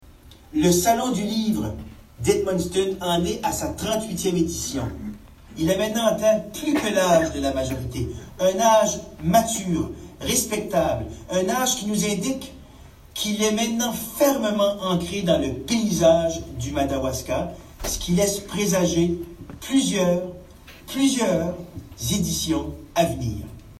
À en juger les milliers de curieux, l’évènement suscite encore et toujours un véritable engouement de la part du public, lequel ne date pas d’hier, comme l’expliquait le député de Madawaska Restigouche René Arsenault en visioconférence lors de la cérémonie d’inauguration.